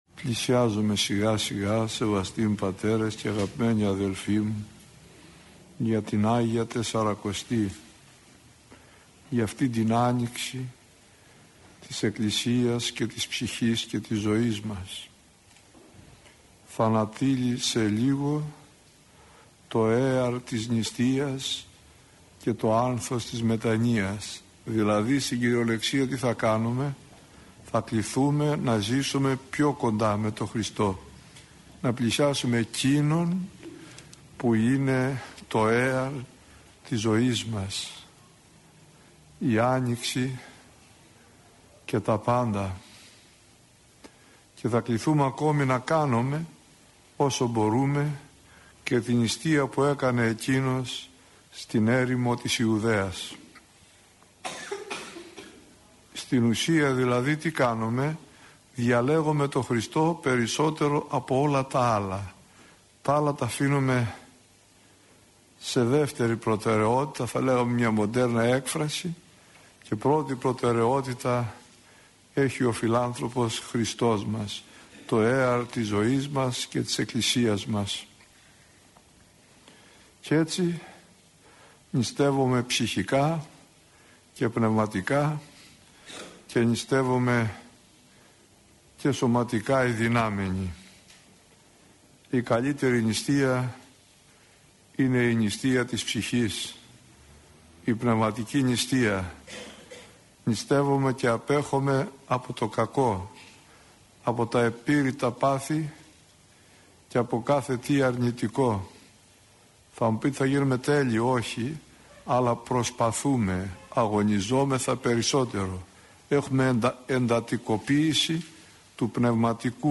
Η εν λόγω ομιλία αναμεταδόθηκε από τον ραδιοσταθμό της Πειραϊκής Εκκλησίας.